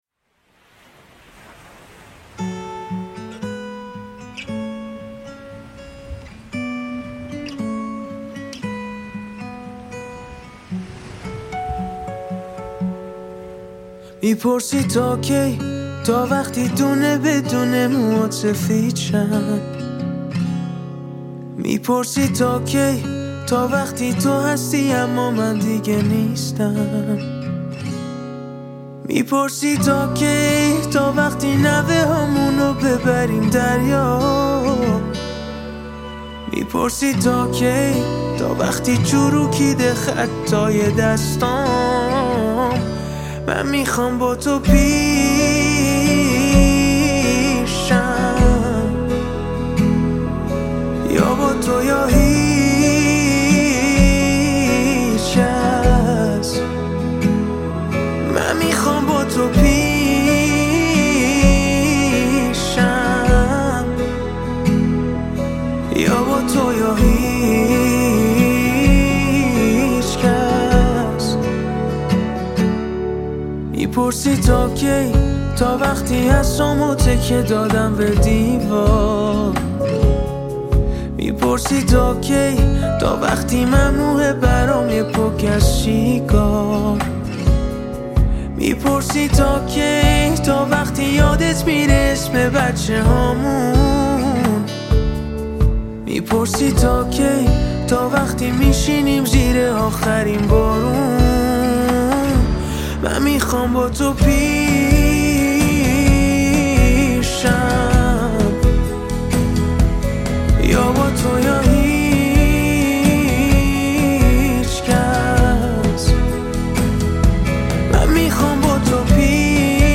پاپ شاد عاشقانه